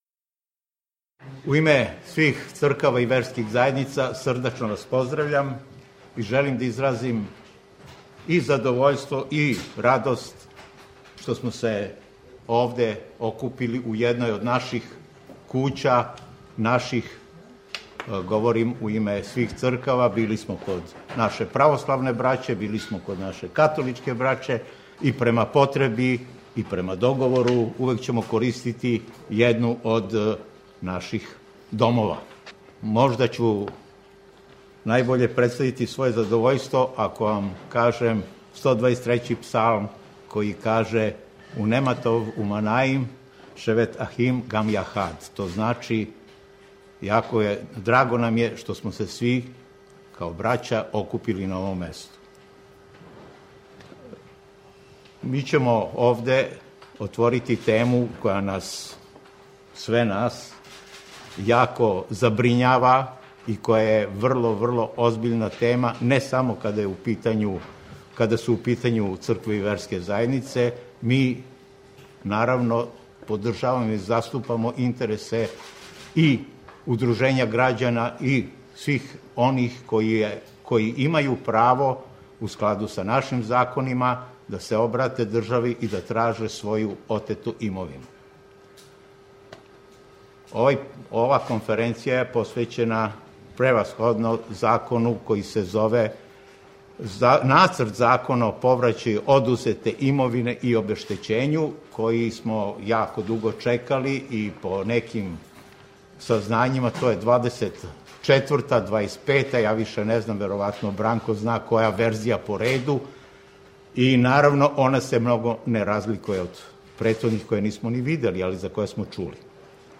Конференција за медије представника традиционалних Цркава и верских заједница у Србији